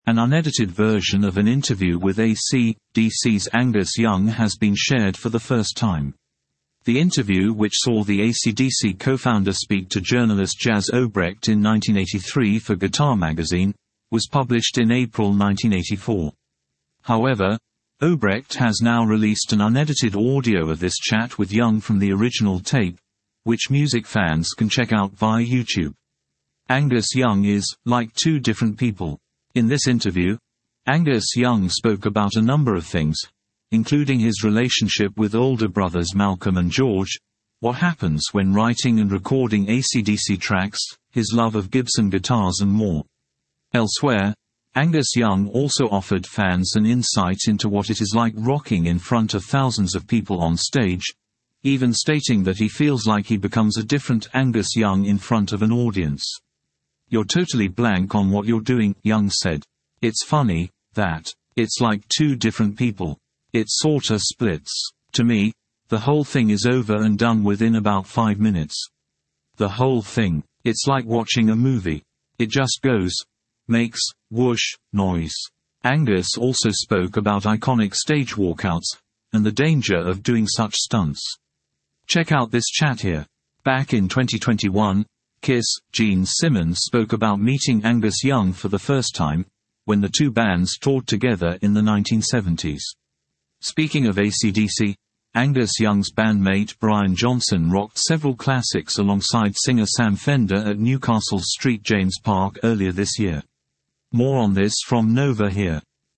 An unedited version of an interview with AC/DC’s Angus Young has been shared for the first time.